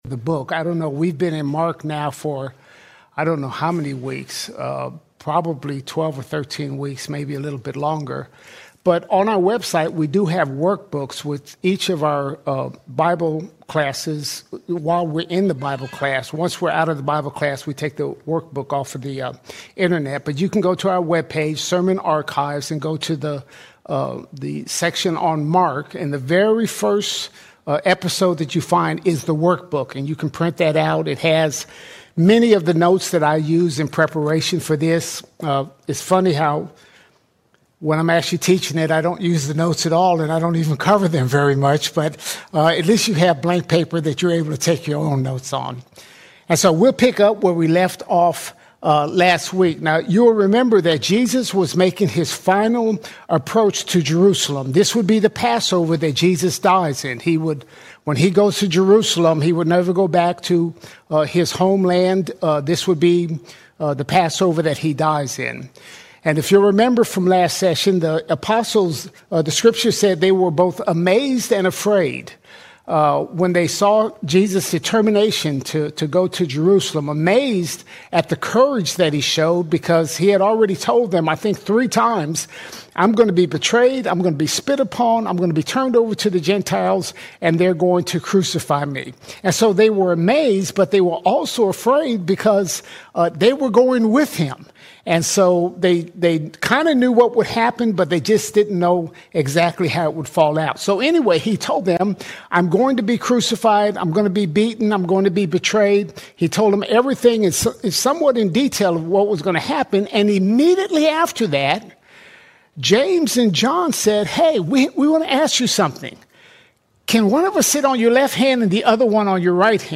20 February 2026 Series: Gospel of Mark All Sermons Mark 10:41 - 11:23 Mark 10:41 – 11:23 Jesus redefines greatness as servanthood, foretells His suffering, and enters Jerusalem as King.